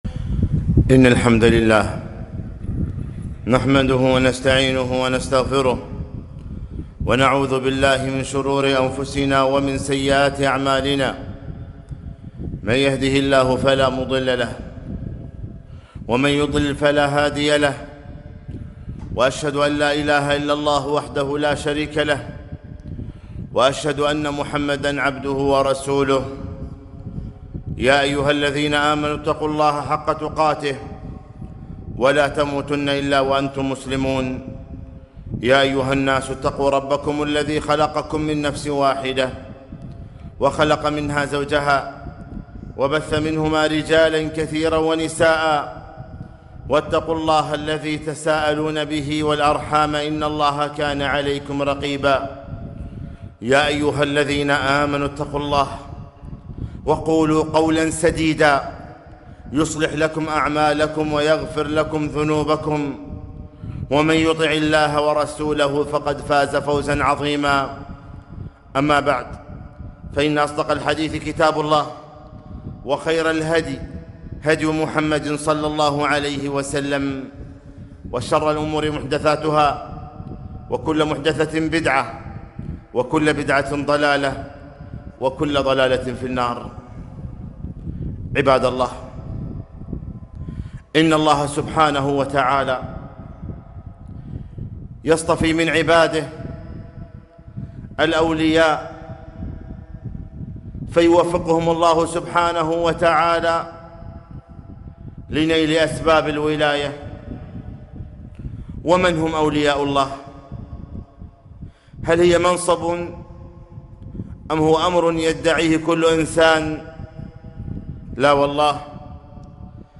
خطبة - أولياء الرحمن